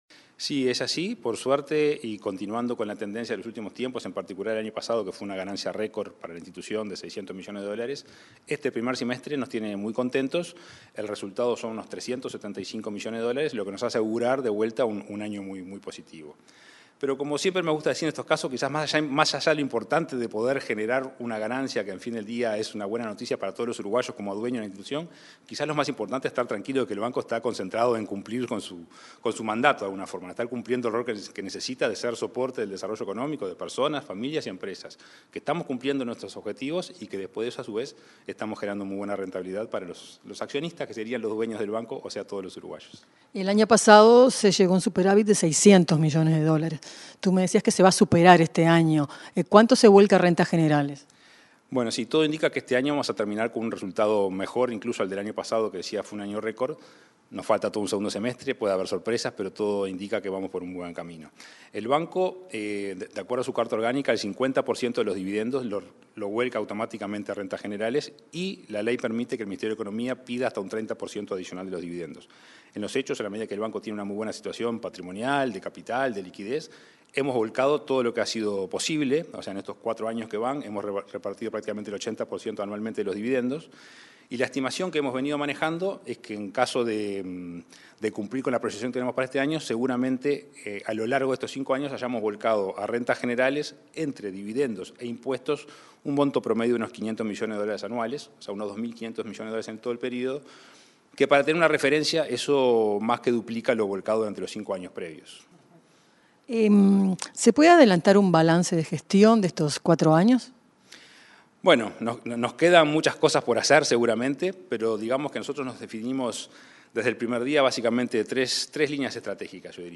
Entrevista al presidente del BROU, Salvador Ferrer
El presidente del Banco de la República (BROU), Salvador Ferrer, dialogó con Comunicación Presidencial, acerca de los resultados del primer semestre